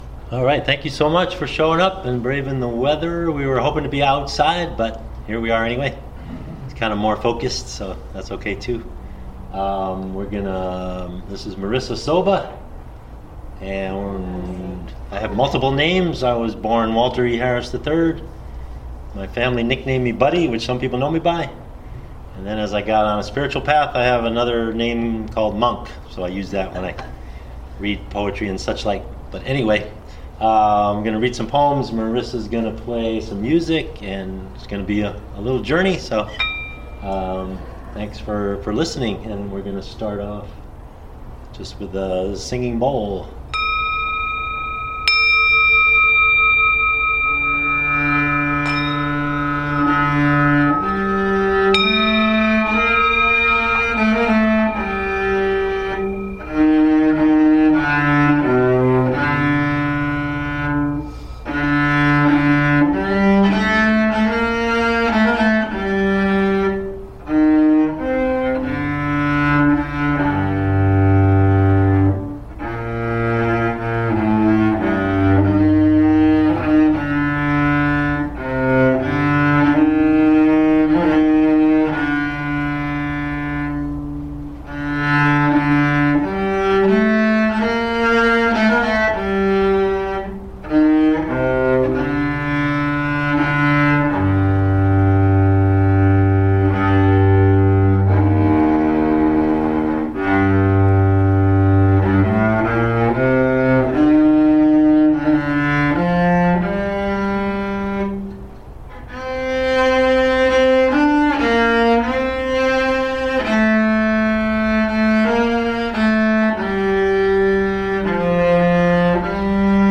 Stanzas & Strings, performed at Sachem Library
cello and electric harp
reading his poems and playing singing bowl
"Soothing, inspirational."